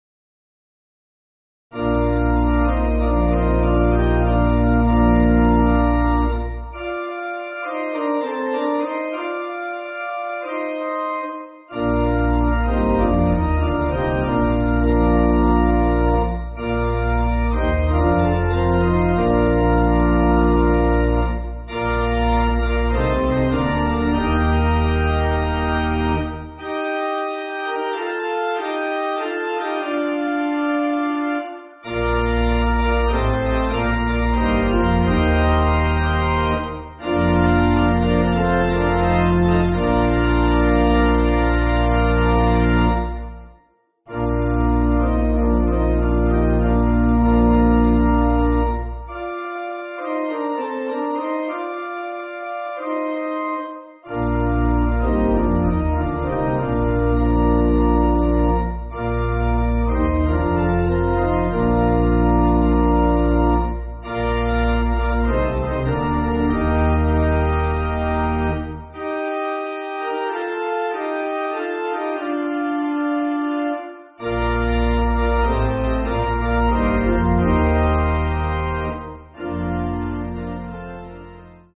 Organ
(CM)   4/Bb